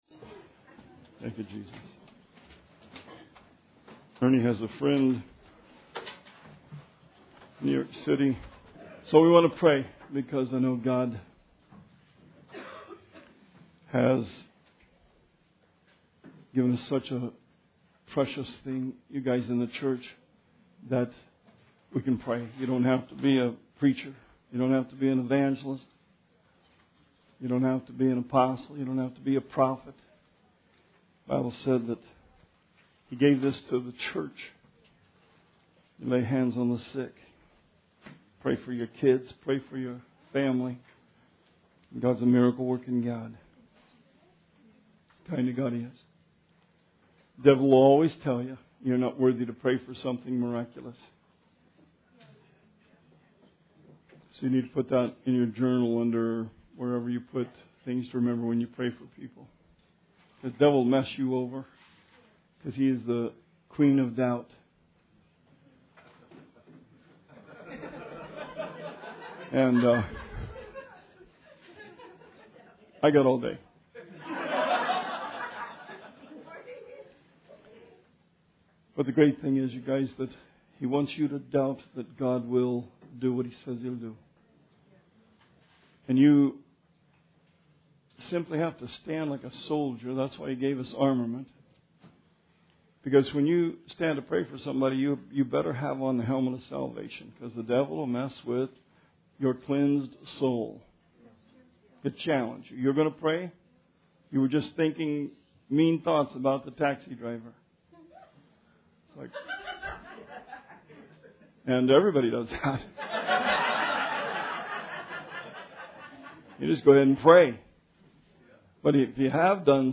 Sermon 7/31/16